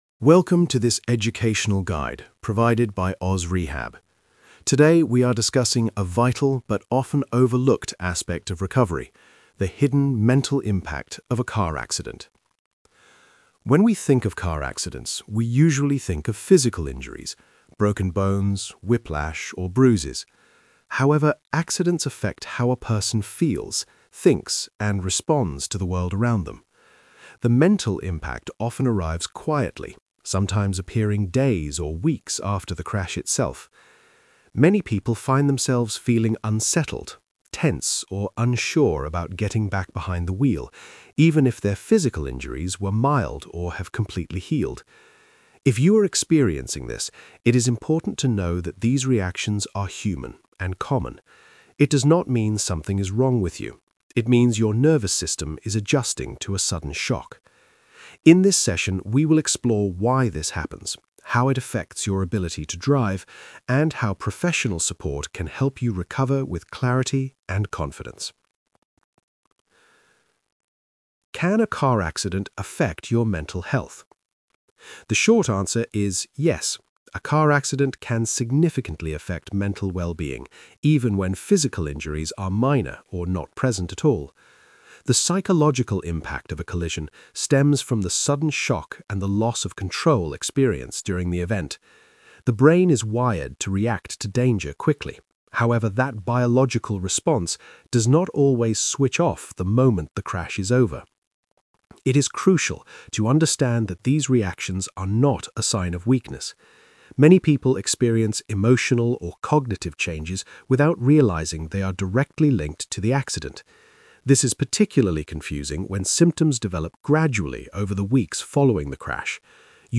🎧 Listen to the audio version Single-host narration